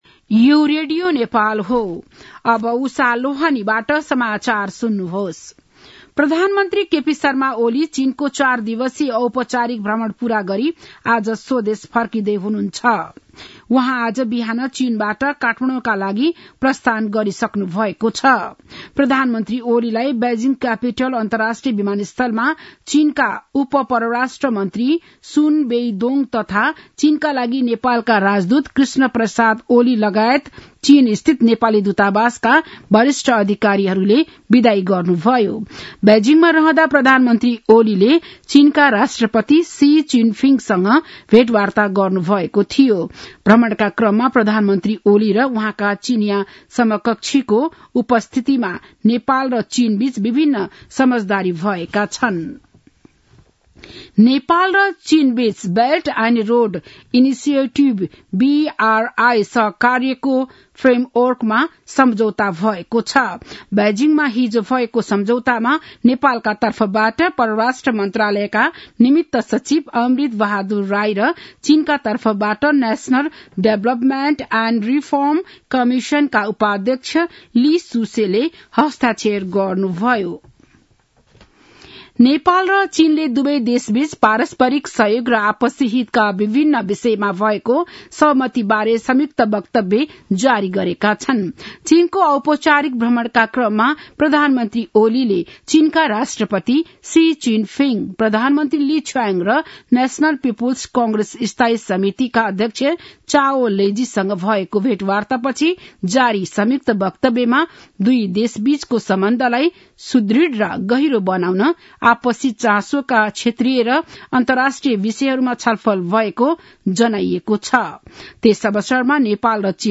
बिहान ११ बजेको नेपाली समाचार : २१ मंसिर , २०८१
11-am-nepali-news-1-4.mp3